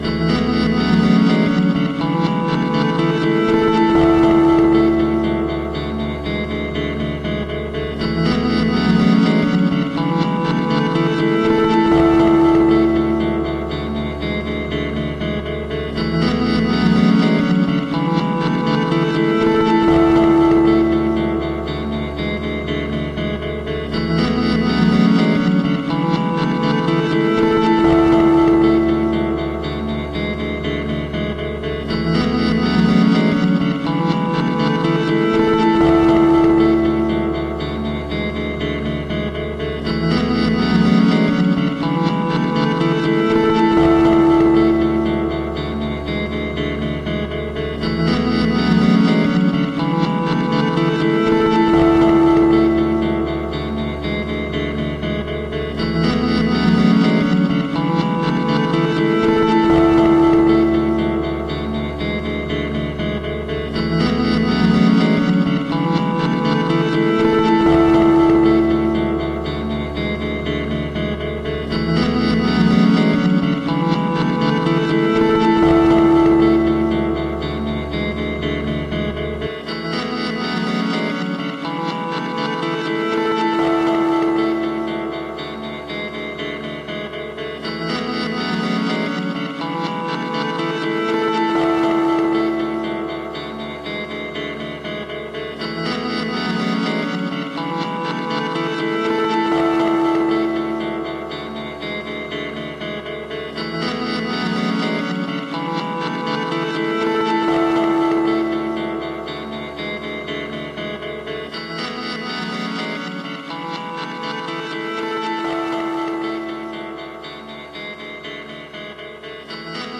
Fourteen notes in just under eight seconds.
Drawing I (02:23), looped with minor treatment.